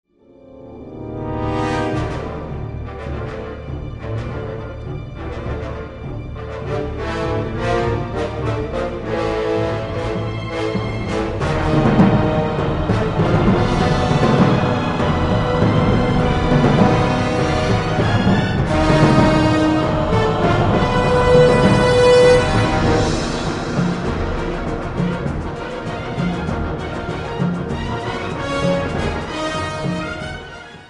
Den bredt malende symfoniske pensel
eget actionmotiv